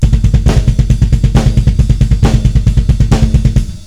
Black Metal Drum Rudiments
Beat 3 - The Bass Ruff
bassruf1.wav